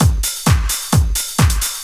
Index of /90_sSampleCDs/Ueberschall - Techno Trance Essentials/02-29 DRUMLOOPS/TE06-09.LOOP-TRANCE/TE08.LOOP-TRANCE3